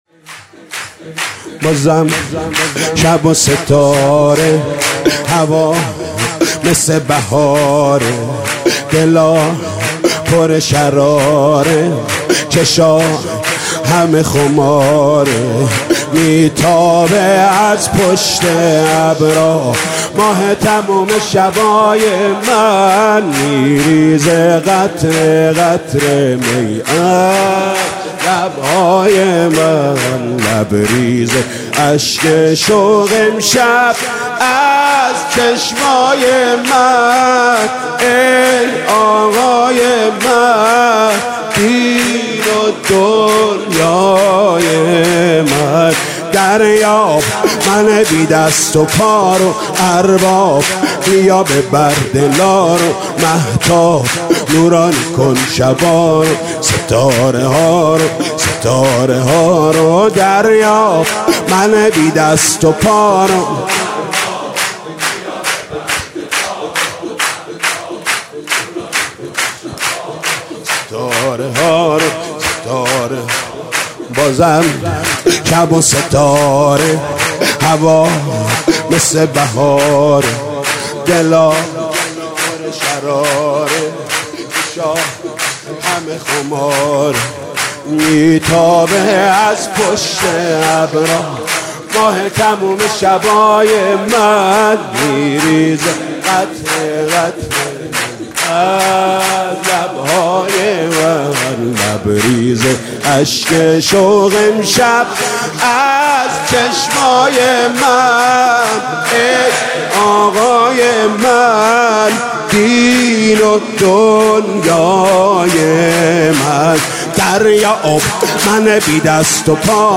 سرود: بازم شب و ستاره